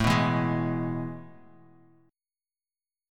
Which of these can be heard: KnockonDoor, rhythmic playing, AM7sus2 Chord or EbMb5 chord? AM7sus2 Chord